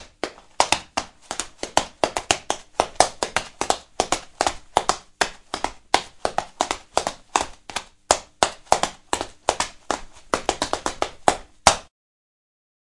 踢踏舞
描述：轻拍在木地板上的鞋子可以用作跳舞或踢踏舞。
Tag: 拉伸 OWI 木材 步伐轻快 跳舞 地板 自来水